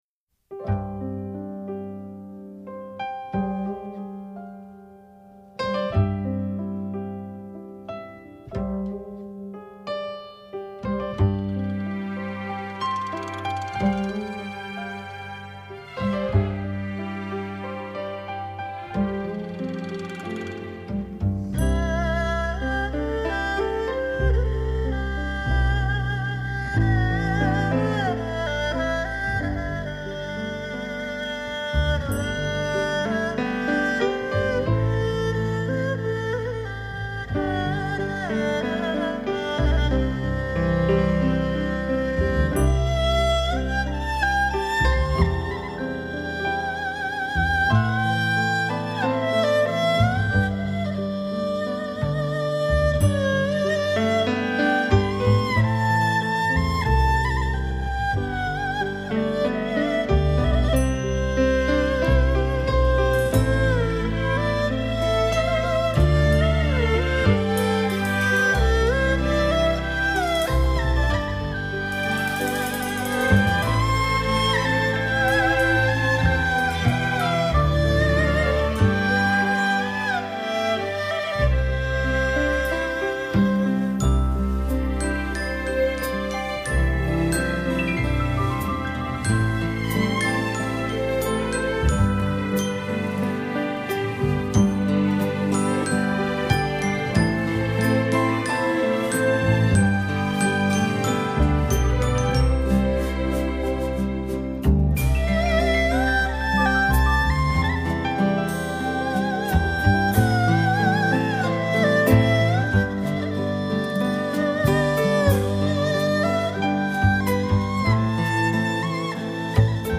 音乐类型: 试音碟